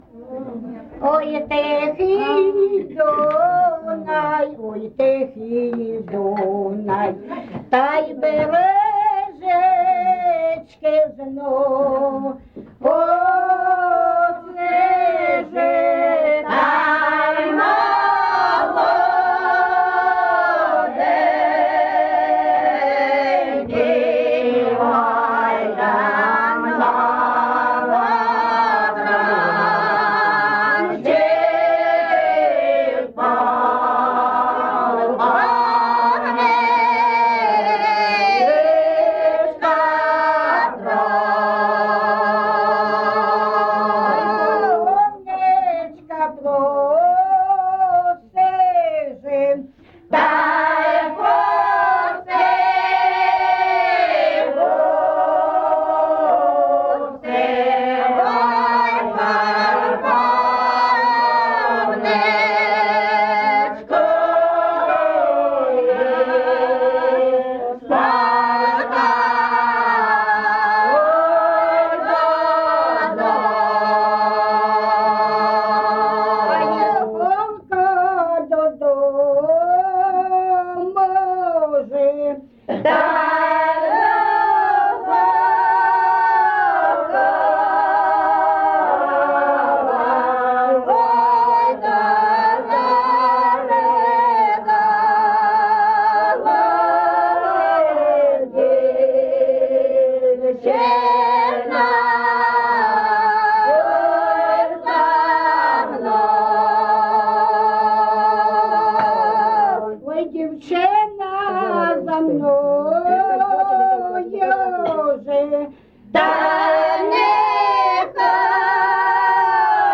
ЖанрПісні з особистого та родинного життя, Козацькі
Місце записус. Шарівка, Валківський район, Харківська обл., Україна, Слобожанщина